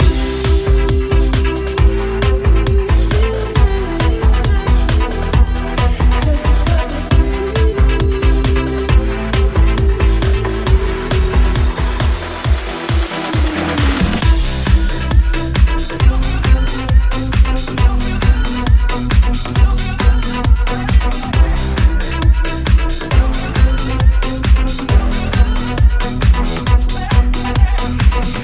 house
Genere: house, deep house, remix